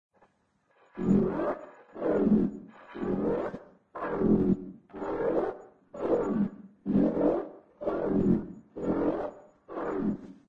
scrap-signal/assets/audio/sfx/footsteps.ogg
footsteps.ogg